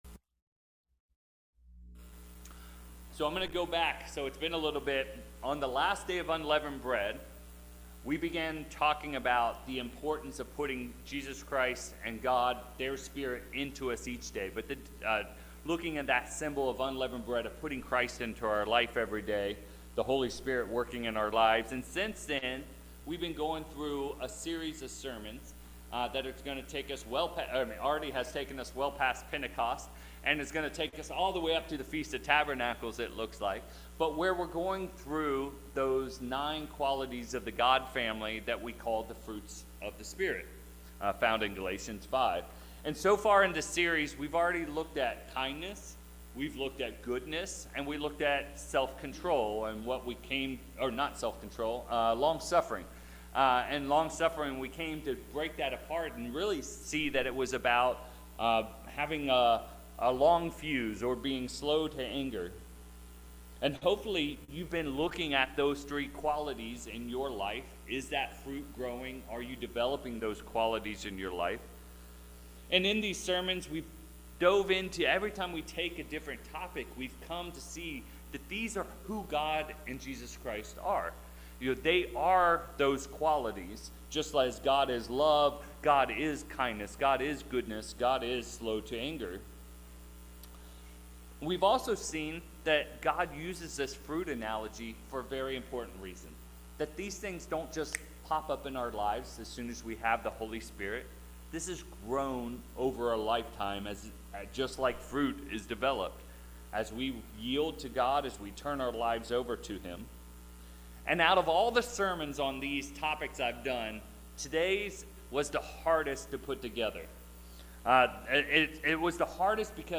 7/13/24 In this fourth sermon on the nine qualities of the God Family, we explore the fruit of Faithfulness. To understand this important quality we will look at the life of the "Father of the Faithful" Abraham and look at how God developed his faith over a lifetime.